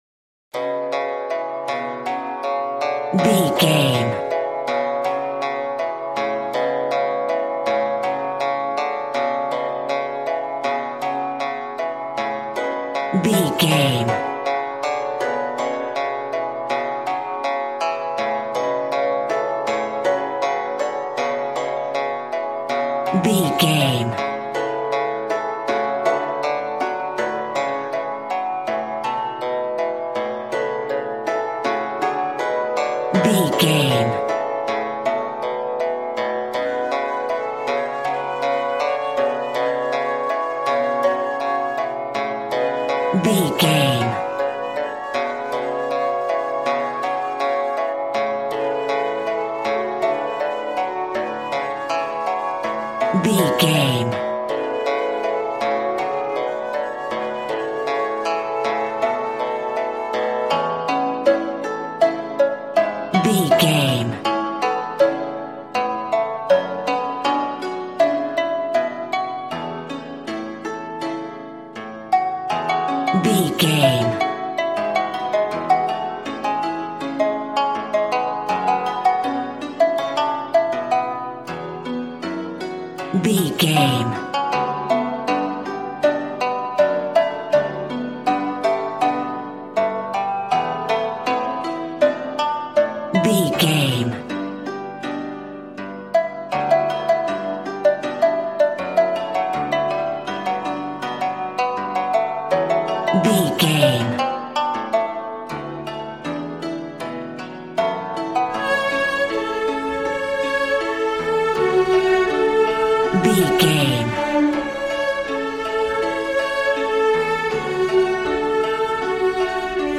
Aeolian/Minor
G♭
happy
bouncy
conga